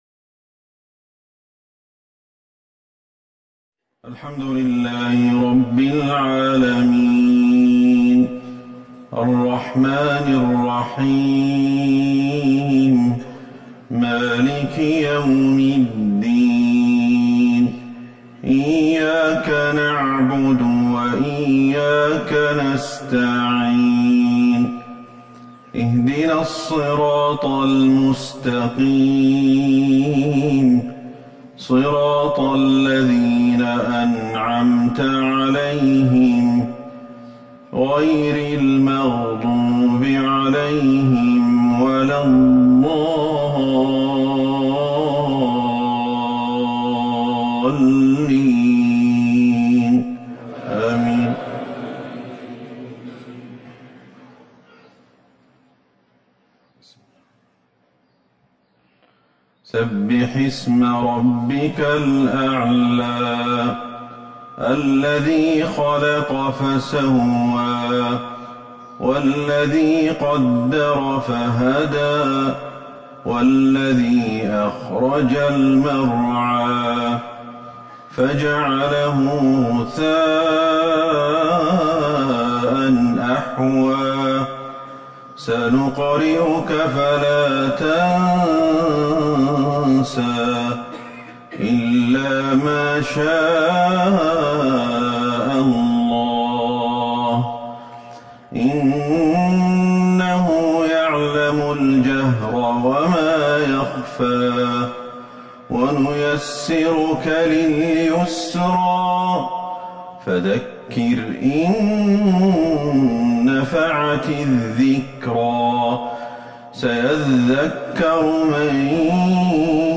صلاة المغرب ١٥ جمادى الآخرة ١٤٤١هـ سورتي الأعلى و التين